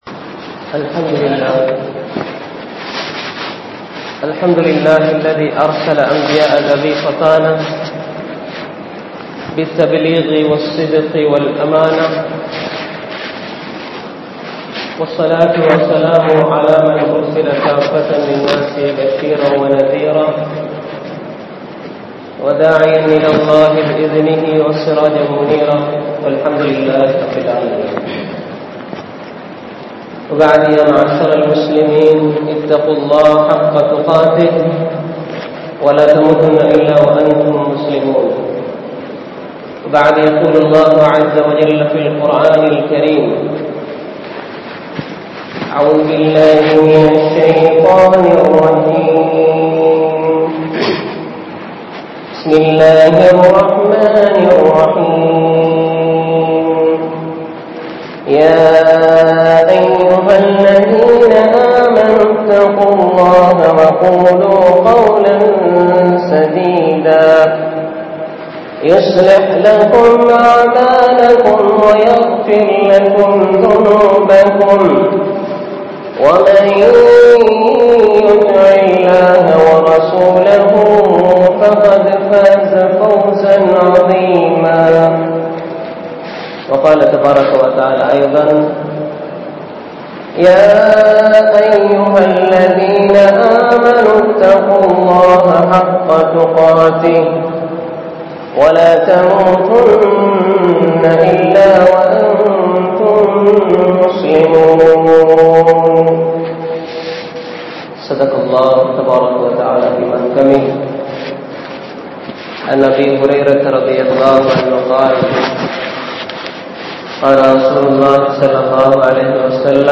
Sarvetheasa Muslimkalin Indraya Nilamai | Audio Bayans | All Ceylon Muslim Youth Community | Addalaichenai
Pallimulla Jumua Masjith